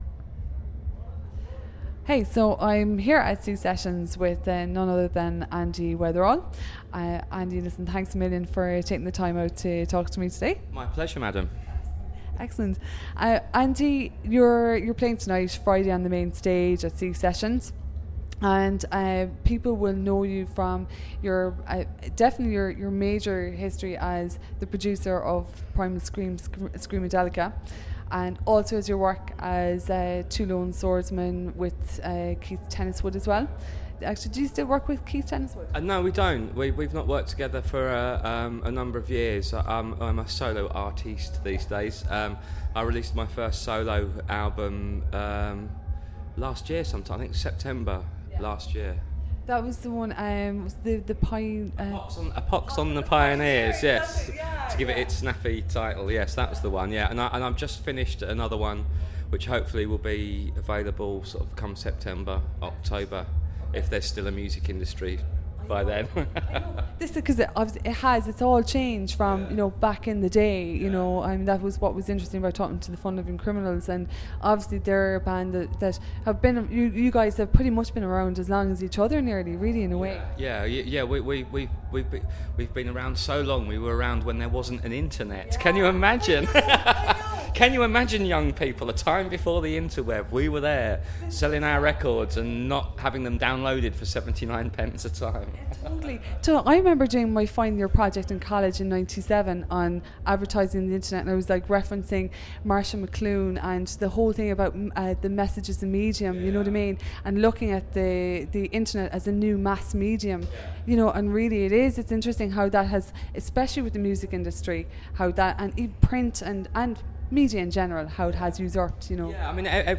Enjoy this lost conversation with one of music’s most idiosyncratic icons.